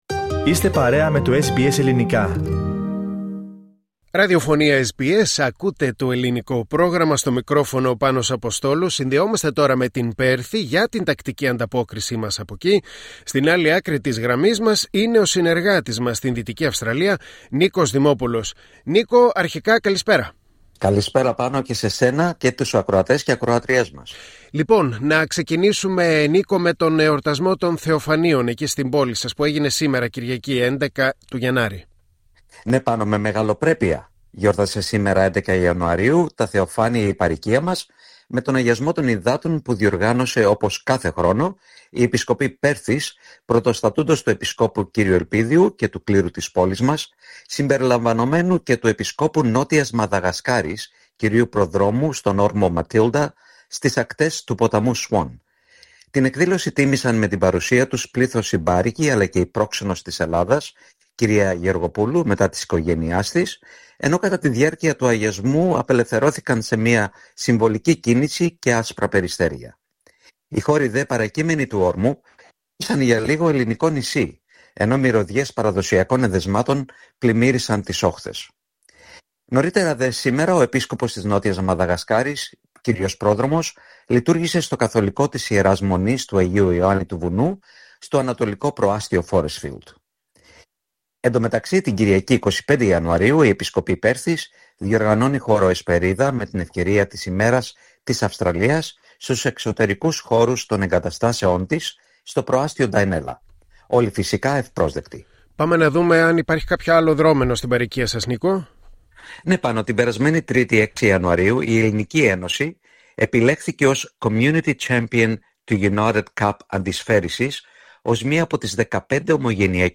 Ακούστε την ανταπόκριση από την Πέρθη